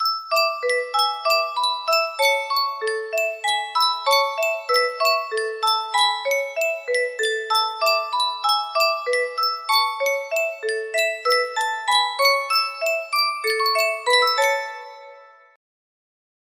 Yunsheng Music Box - The Blue Danube Waltz 003Y music box melody
Full range 60